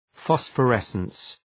Προφορά
{,fɒsfə’resəns}